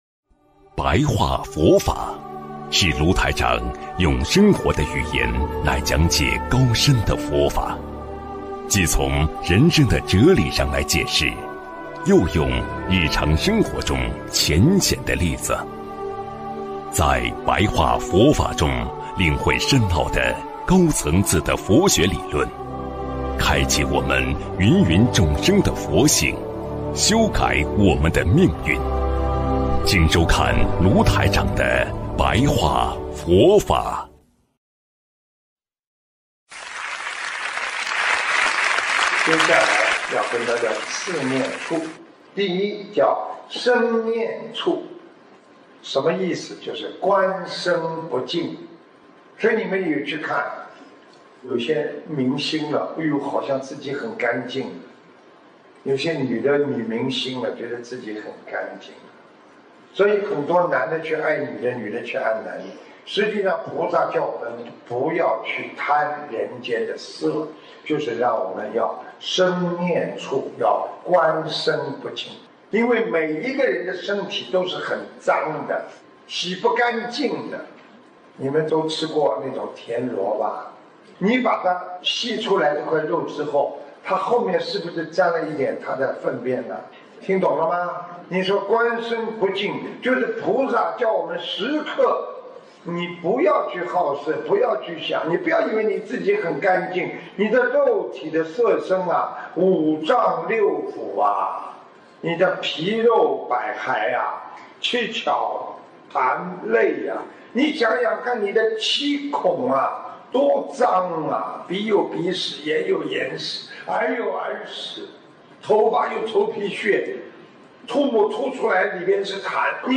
首页 >>佛法书籍 >> 广播讲座